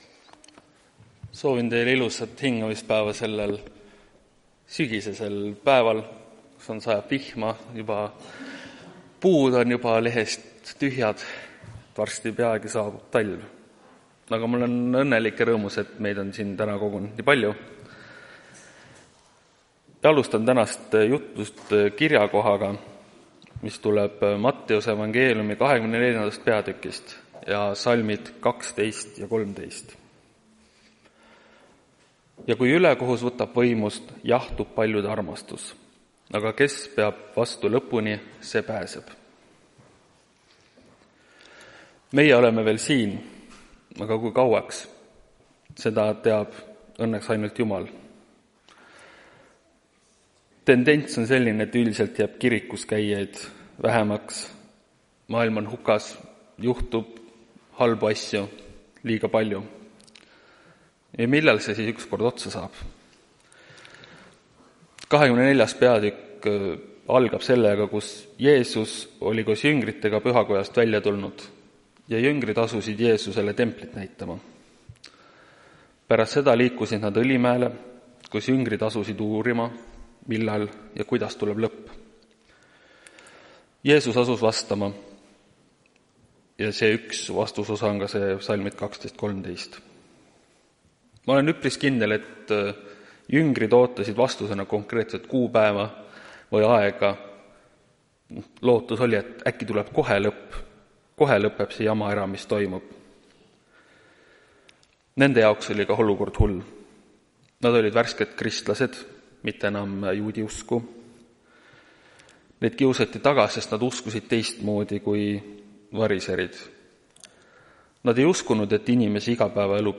Tartu adventkoguduse 26.10.2024 hommikuse teenistuse jutluse helisalvestis.